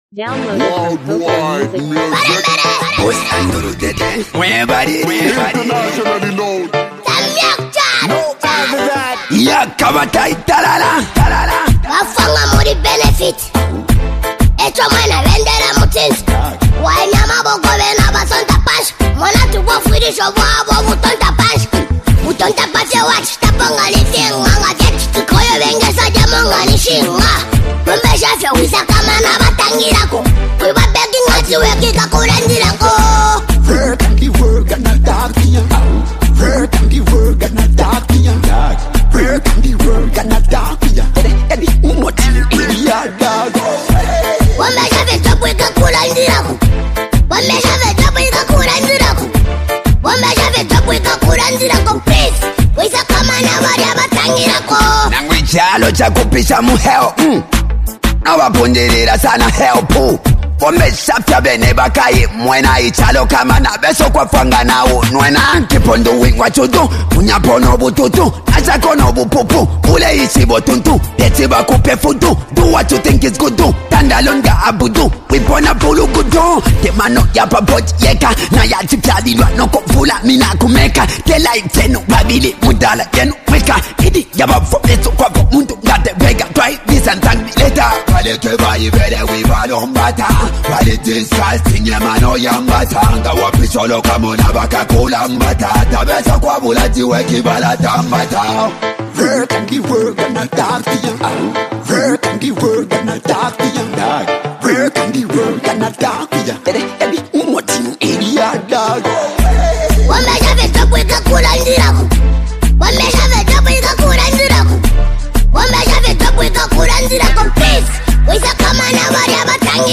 powerful and uplifting track
The chorus is catchy and memorable
inspiring love anthem